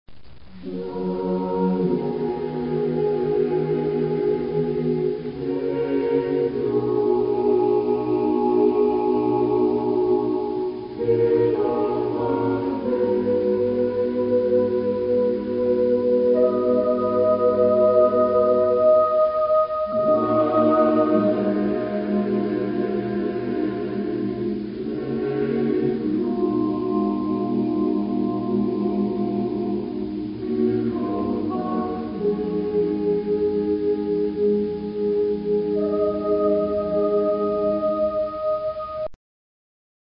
Genre-Style-Form: Secular ; Choir ; Romantic
Mood of the piece: larghetto ; calm
Type of Choir: SAATB  (5 mixed voices )